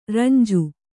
♪ ranju